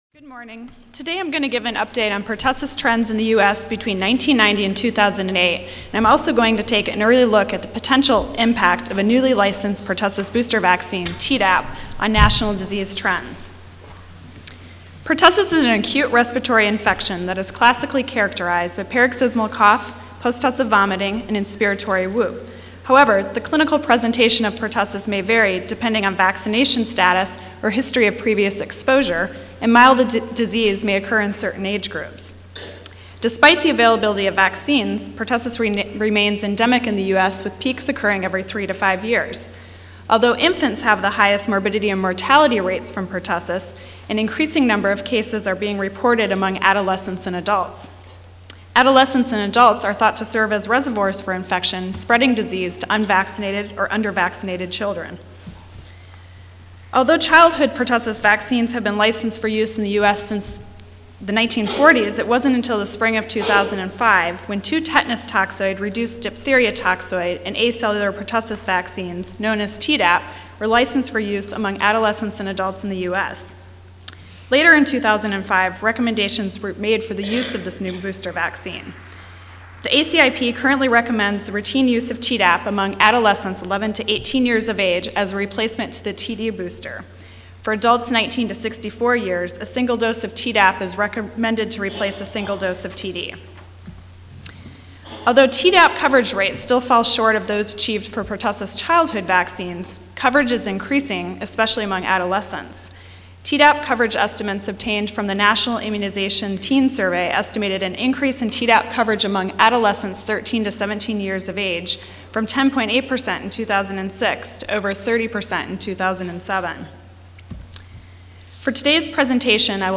43rd National Immunization Conference (NIC): This Ain�t Our First Rodeo�Rounding Up Vaccine-Preventable Disease
Recorded presentation